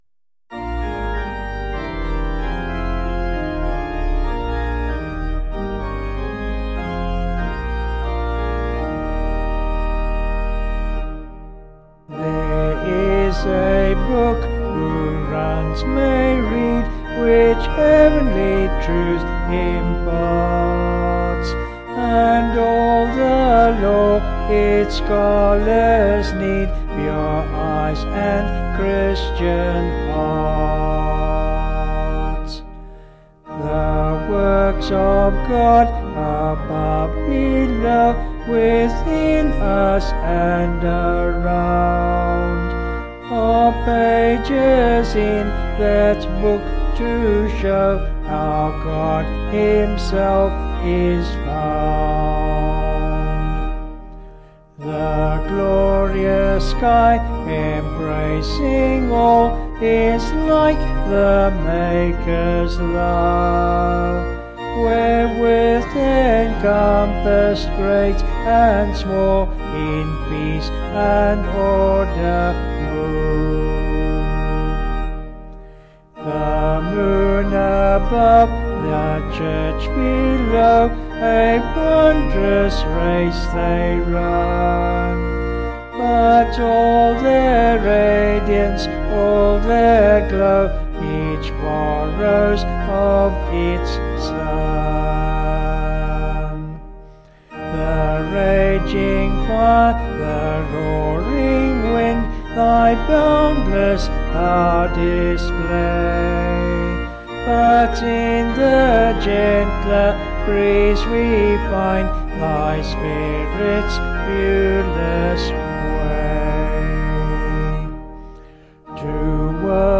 Vocals and Organ   264.8kb Sung Lyrics